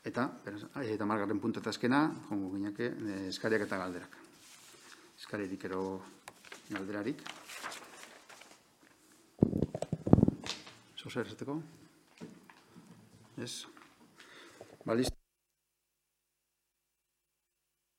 Sesión ordinaria del pleno del Ayuntamiento celebrada el día 28 de julio de 2021, a las 19:00h.
Iñaki Tororikaguena Sarrionandia, Alcalde - EAJ-PNV (00:00:27)
Liher Aiartzaguena Bravo, Portavoz - EH BILDU (00:00:38)